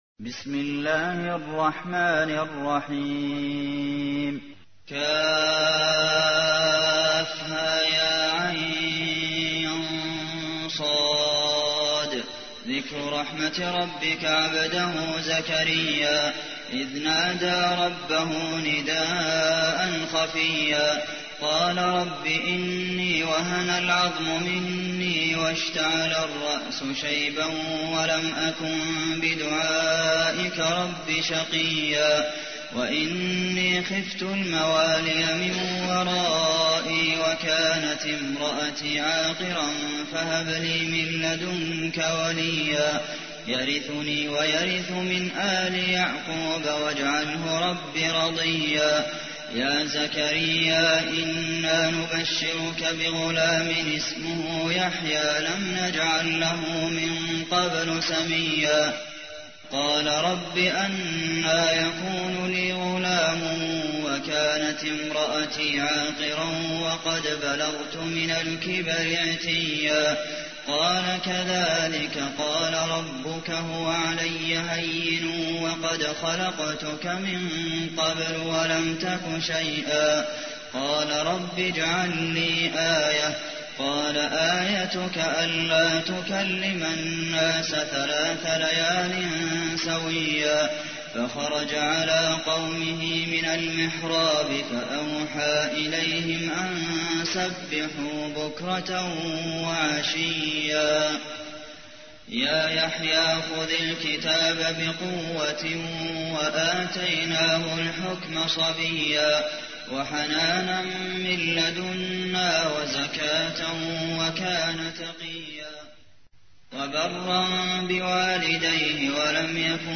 تحميل : 19. سورة مريم / القارئ عبد المحسن قاسم / القرآن الكريم / موقع يا حسين